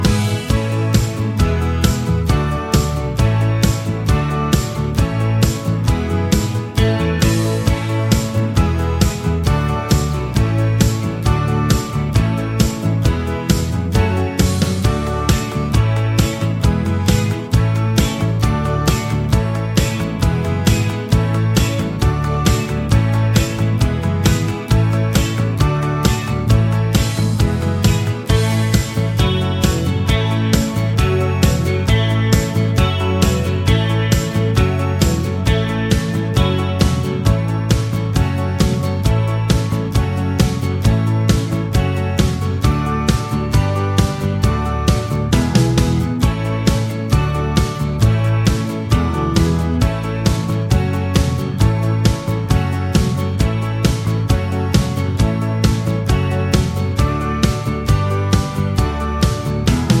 no Backing Vocals Glam Rock 3:38 Buy £1.50